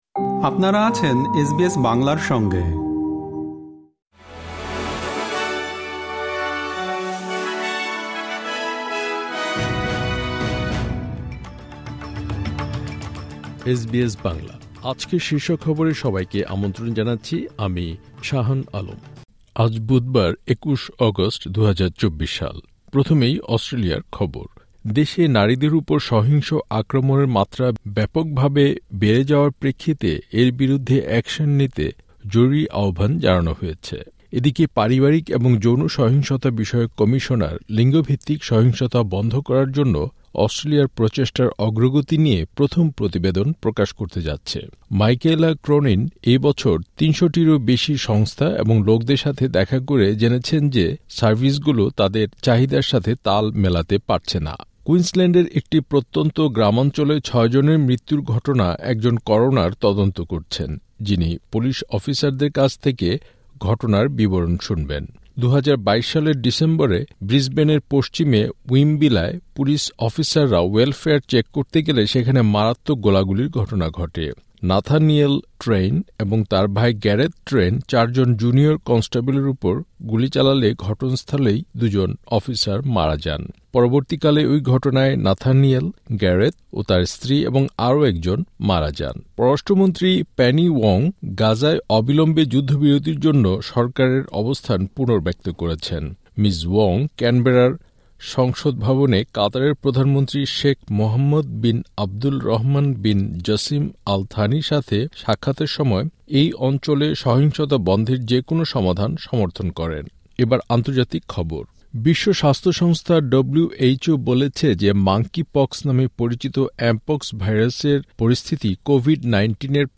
এসবিএস বাংলা শীর্ষ খবর: ২১ অগাস্ট, ২০২৪